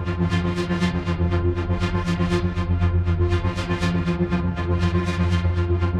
Index of /musicradar/dystopian-drone-samples/Tempo Loops/120bpm
DD_TempoDroneB_120-F.wav